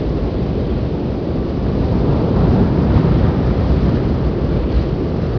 wind21.ogg